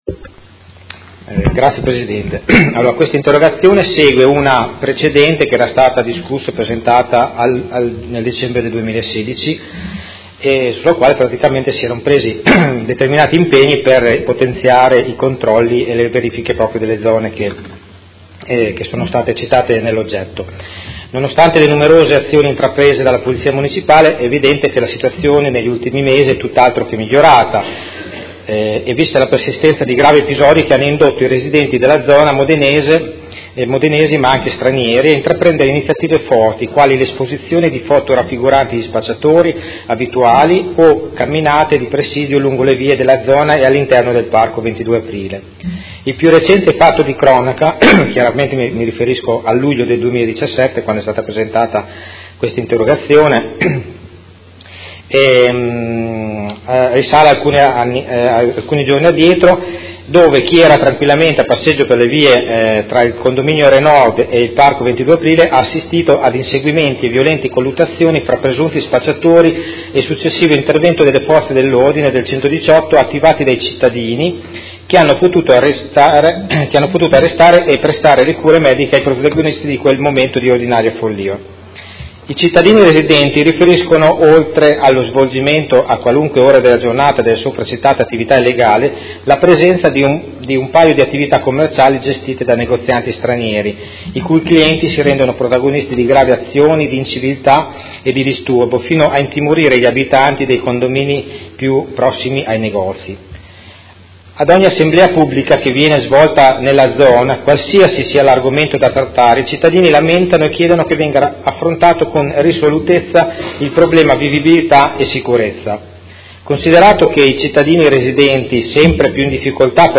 Vincenzo Walter Stella — Sito Audio Consiglio Comunale